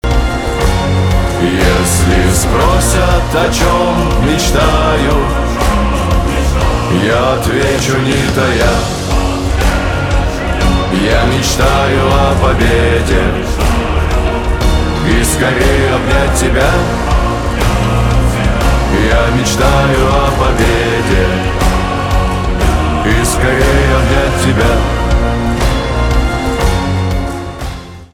поп
чувственные , хор